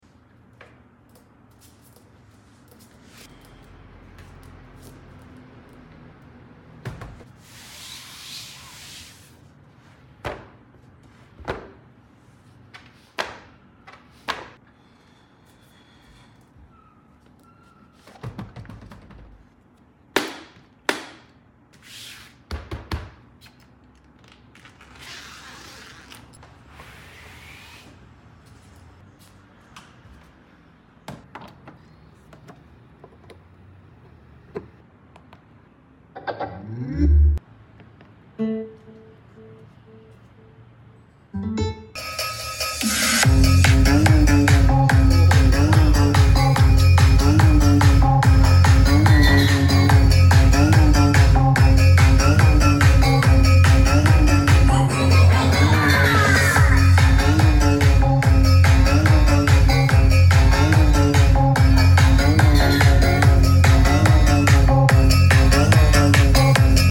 JBL PartyBox Ultimate 1100W Setup + Sound Test! Bag Cover & Light Show Review!”